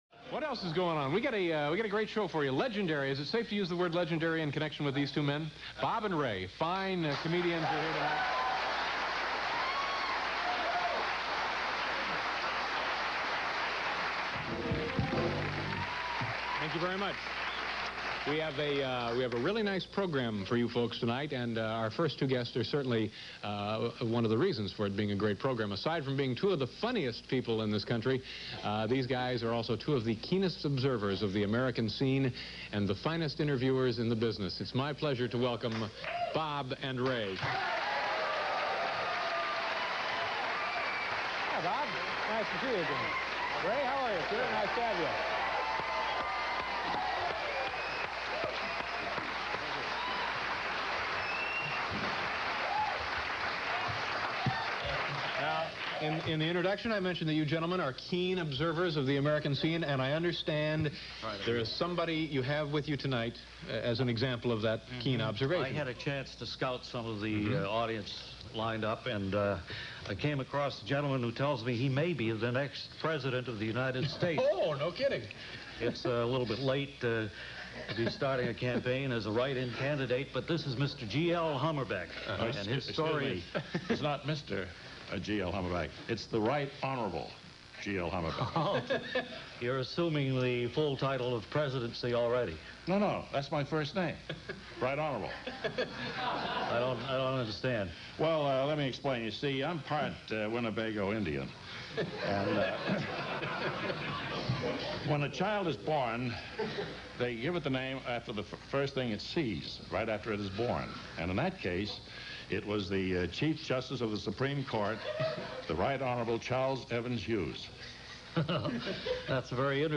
Here’s the audio from a 70’s appearance on David Letterman’s Late Night NBC series. Letterman mentions their recent Carnegie Hall appearances.